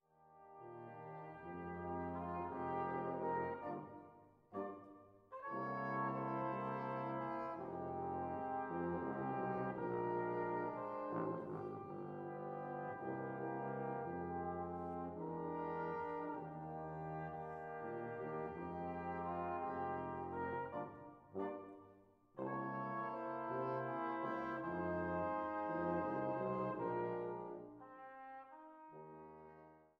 Große Sinfonik für fünf Bläser